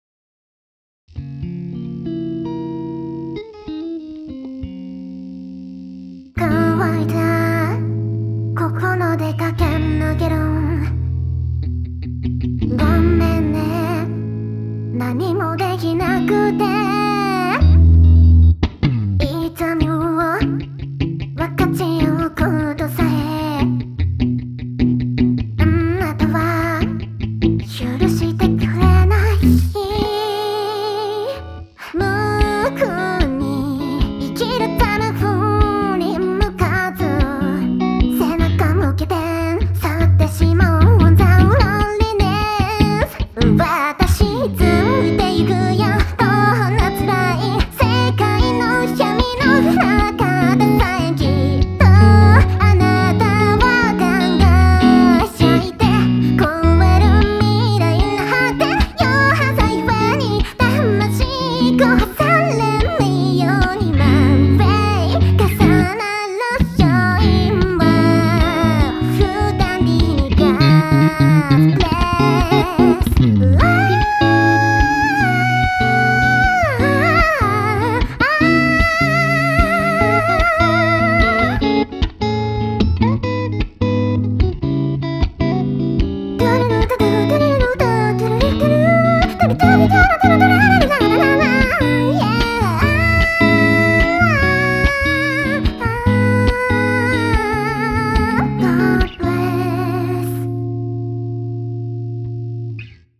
イントロも弾いたよ…